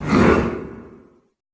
Index of /90_sSampleCDs/Best Service - Extended Classical Choir/Partition I/DEEP SHOUTS
DEEP HMM  -R.wav